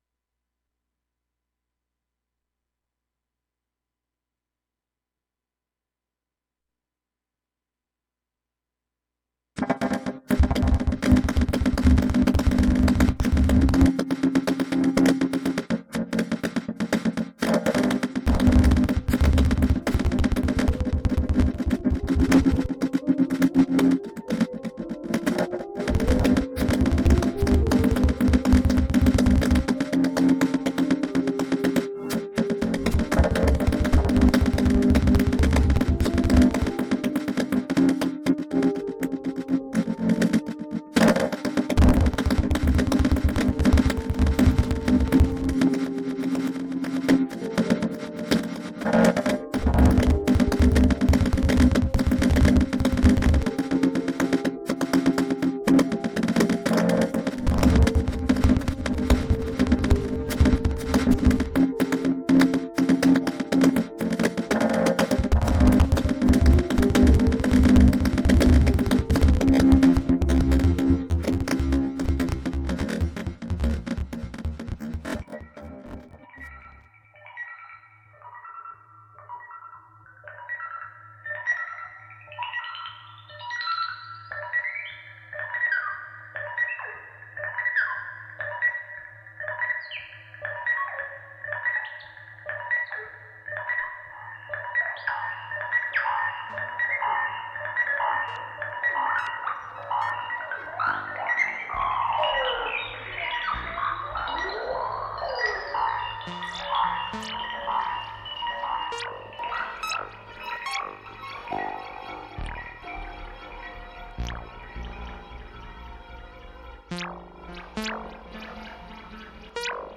Ambient Electronic Experimental